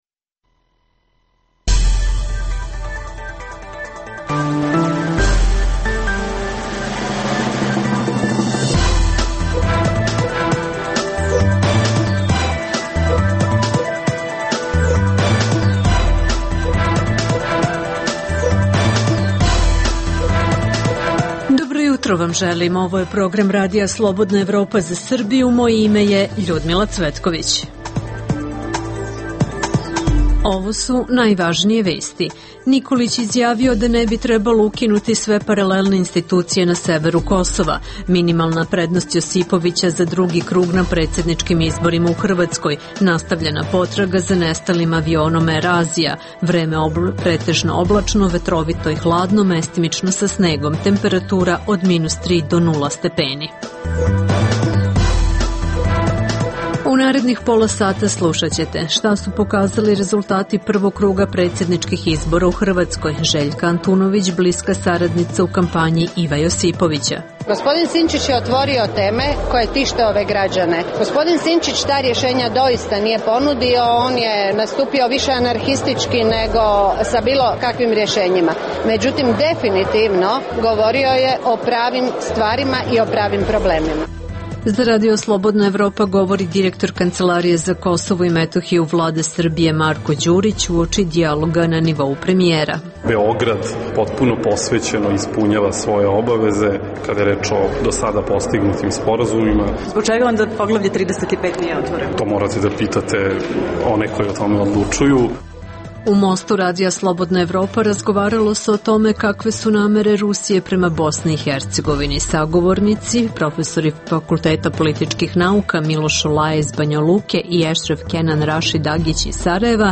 Sa terena izveštavaju naši dopisnici.